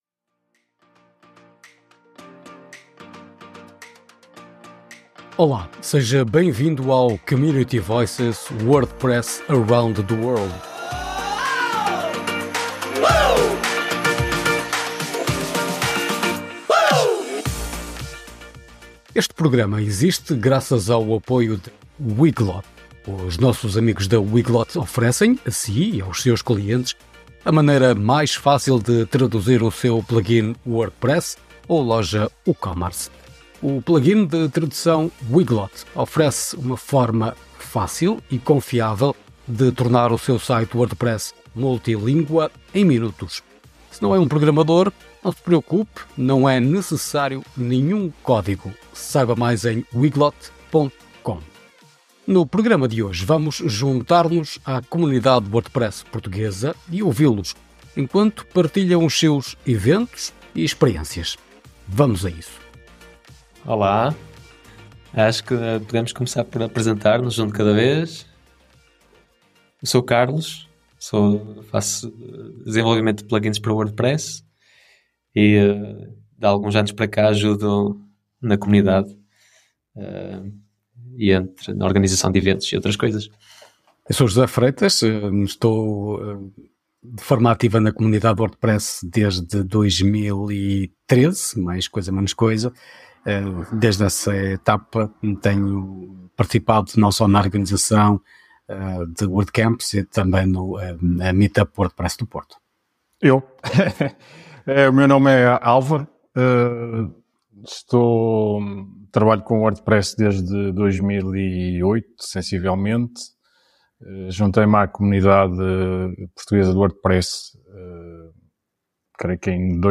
No nosso programa Vozes da Comunidade, WordPress pelo Mundo, destacamos a comunidade WordPress de Portugal para uma conversa na sua língua materna.